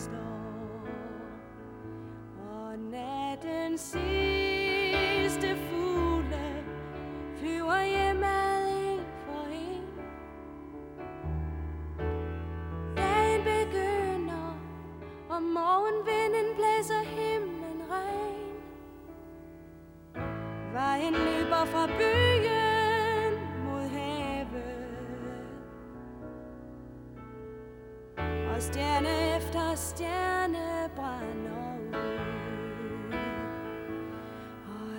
Pop Rock Adult Alternative Alternative College Rock
Жанр: Поп музыка / Рок / Альтернатива